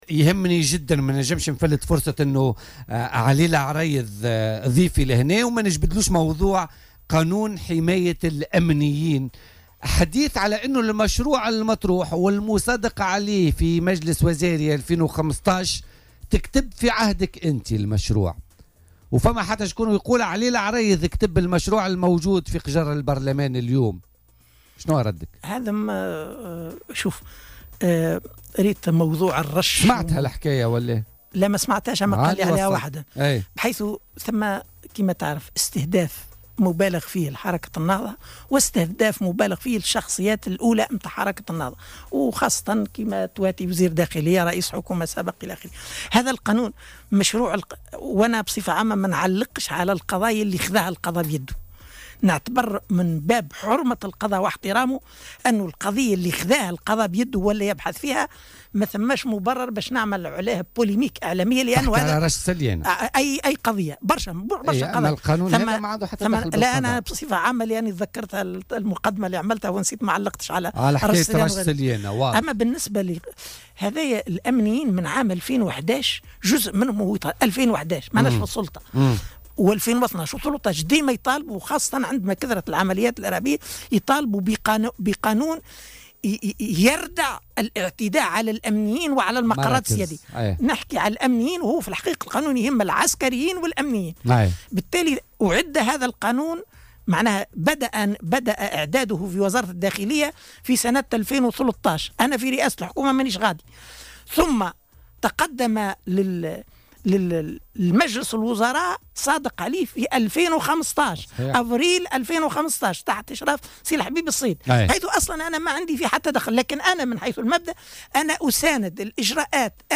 في المقابل شدّد العريض، ضيف "بوليتيكا" اليوم، على ضرورة حماية الحريات الفردية، وعدم المساس بها في اطار قانون حماية الأمنيين، داعيا إلى التعجيل في المصادقة عليه وتمريره.